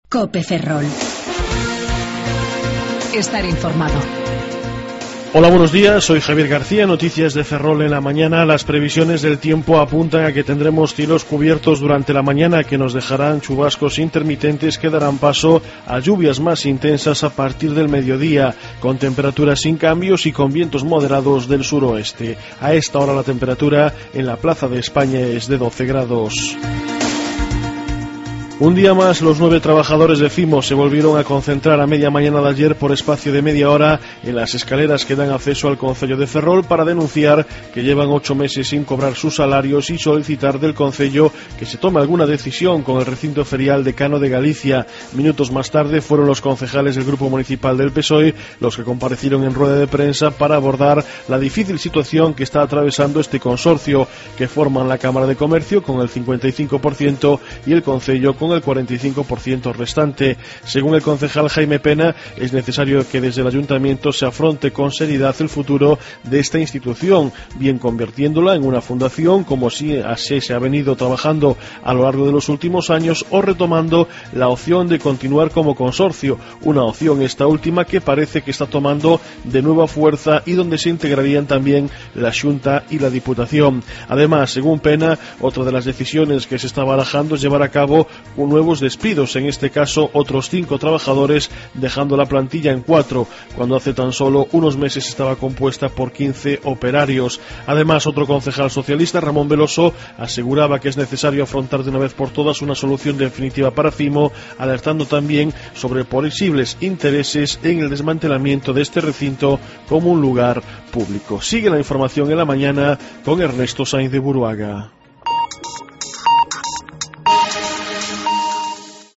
07:58 Informativo La Mañana